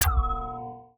Universal UI SFX / Clicks
UIClick_Menu Wooble Metalic Resonate 01.wav